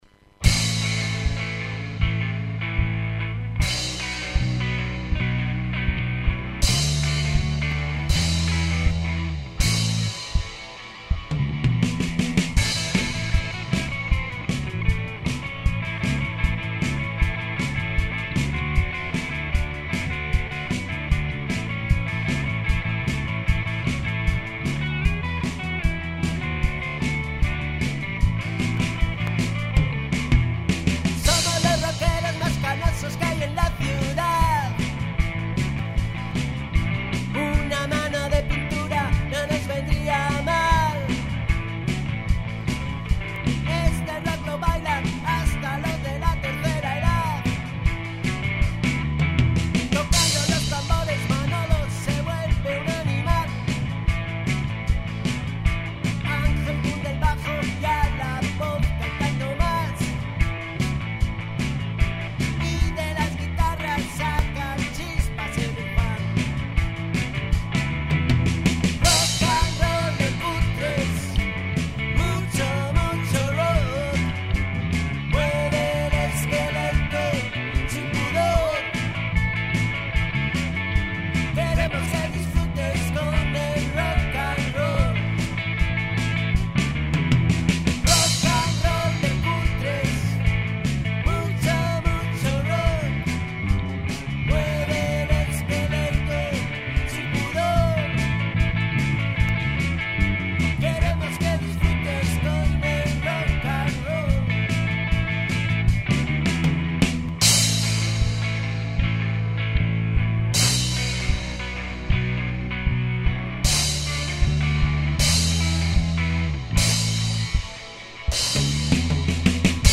Guitarra solista
Bajo
Batería
Guitarra rítmica y coros y voz final